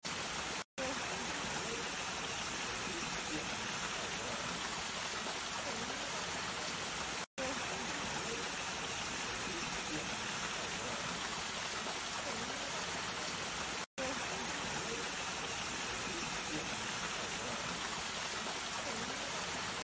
湧き水の音も素晴らしい！